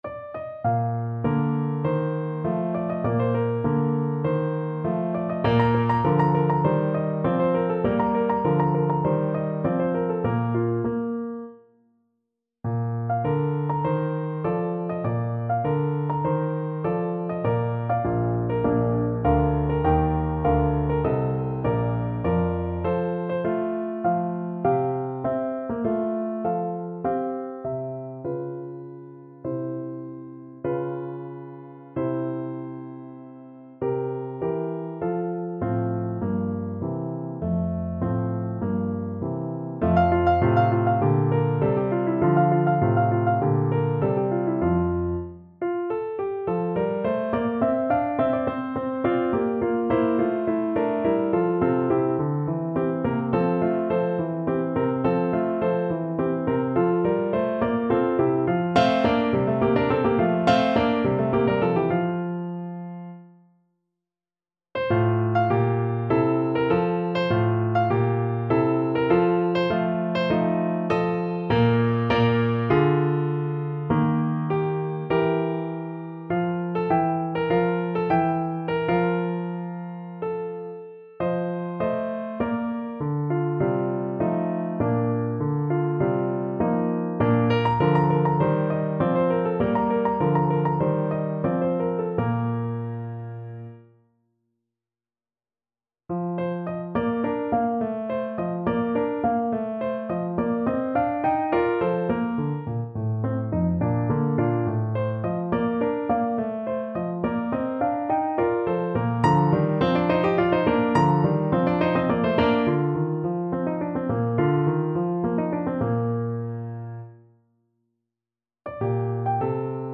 ~ = 50 Larghetto
2/4 (View more 2/4 Music)
Classical (View more Classical Soprano Voice Music)